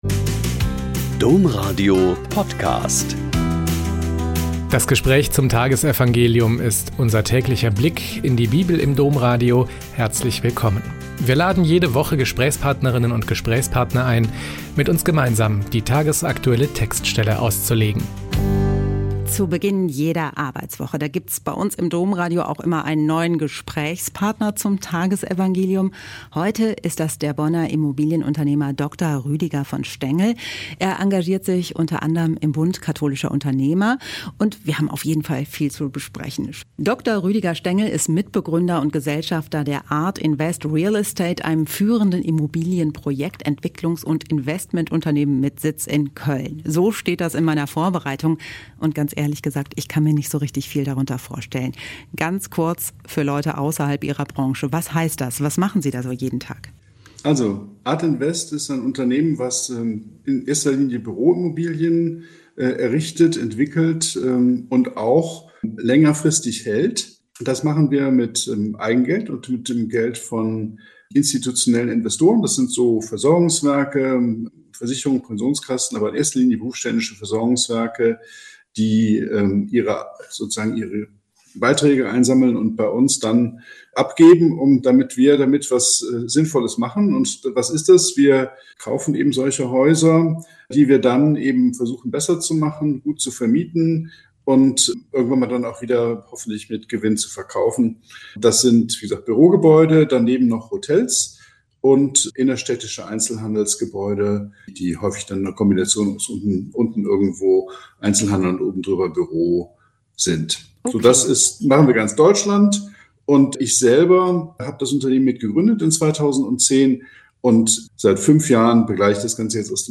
Joh 6,22-29 - Gespräch